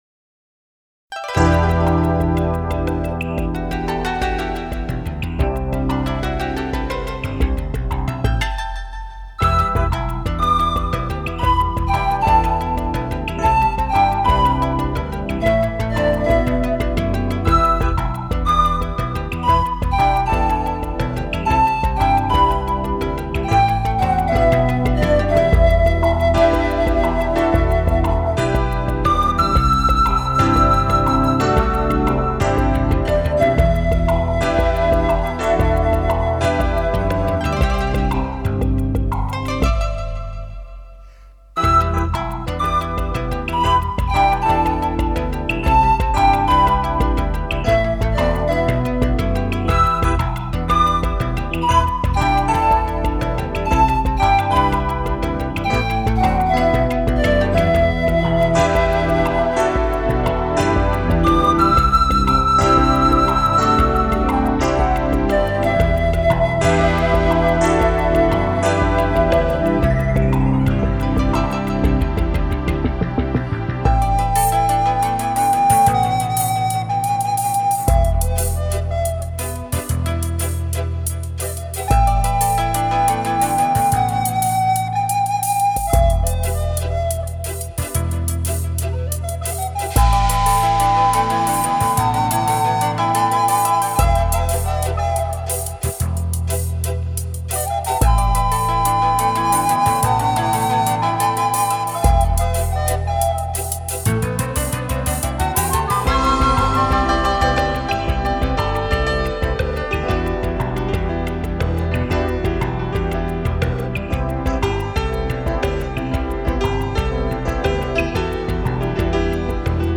New age Медитативная музыка Нью эйдж